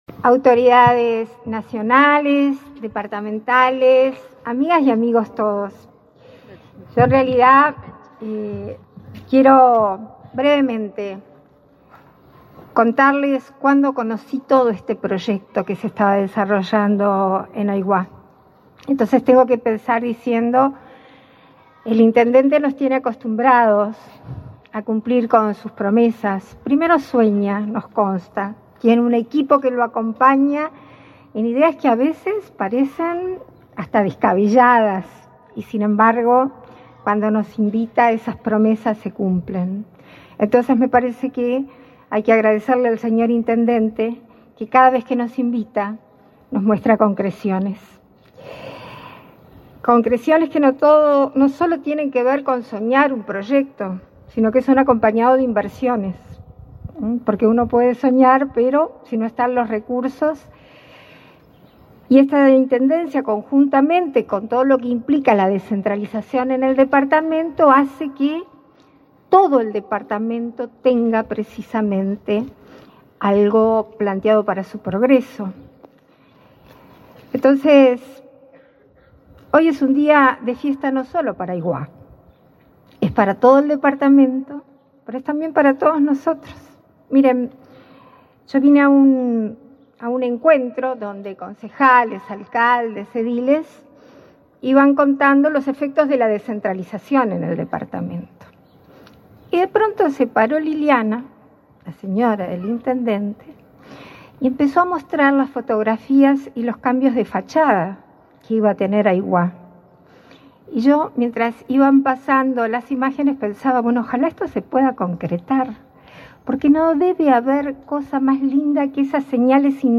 Conferencia de prensa por inauguración de obras en Aiguá
Participaron en el evento la vicepresidenta de la República, Beatriz Argimón, y el secretario de la Presidencia, Álvaro Delgado.